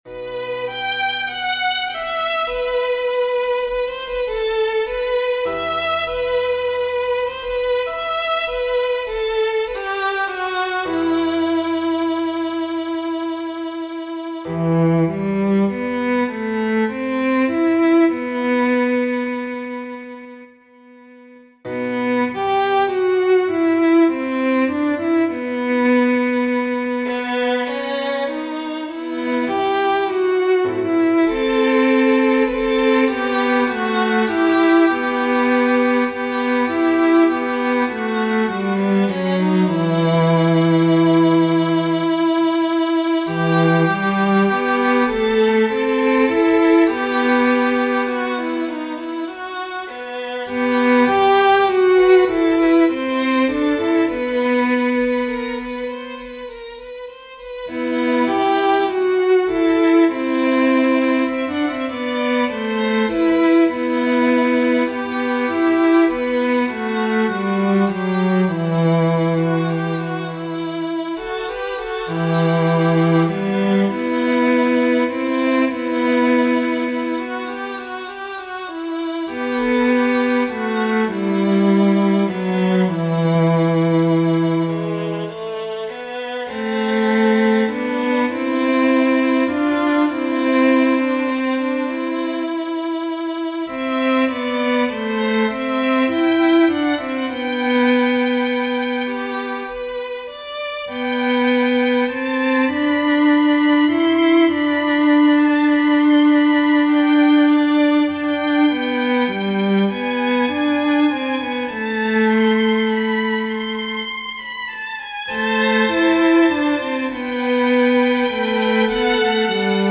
ליווי בכינור - אלתור בסגנון אמנותי
לאחר הרבה הקשבה יצרתי קובץ שמע ותווים המחקה חלק מסגנון הליווי של הכנר האגדי יצחק פרלמן.
זה טוב לקריינות ברקע כמו על השואה